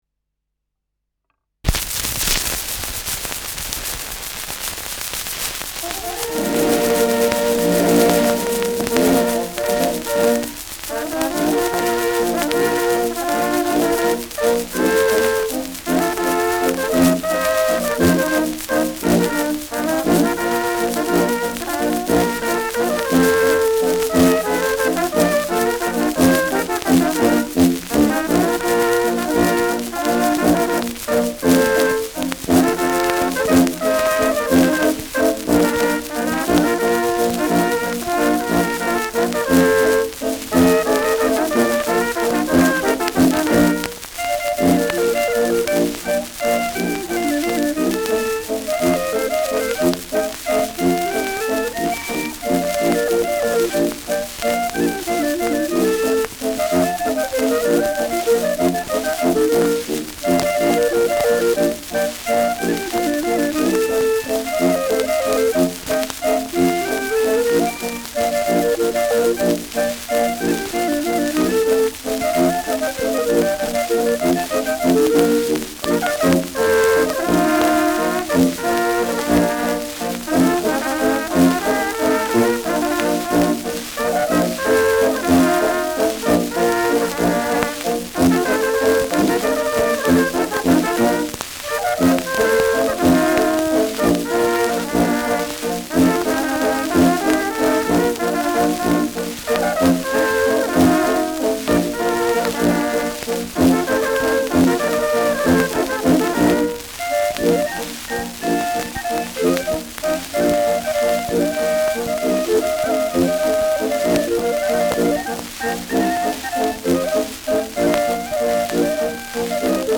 Schellackplatte
präsentes Rauschen : Knistern